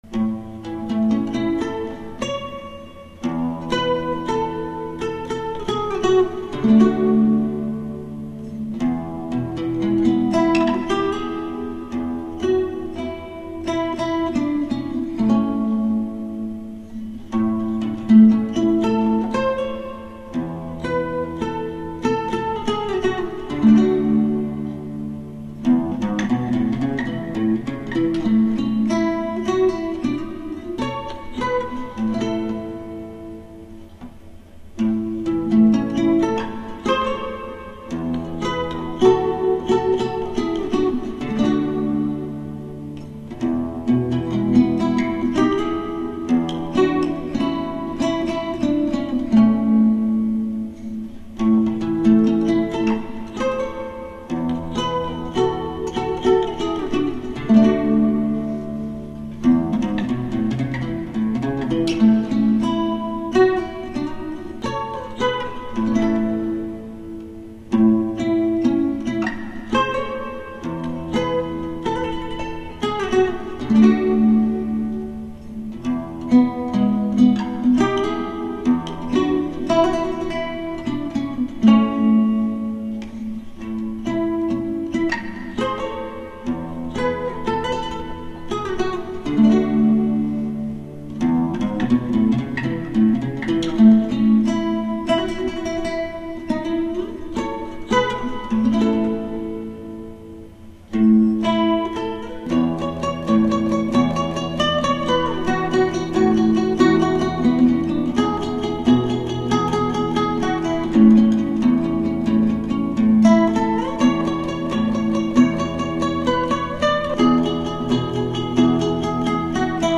0003-吉他名曲少女的祈祷.mp3